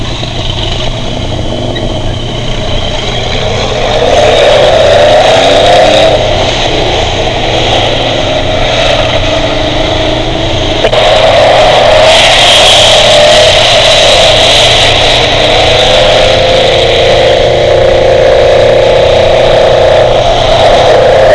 engine.wav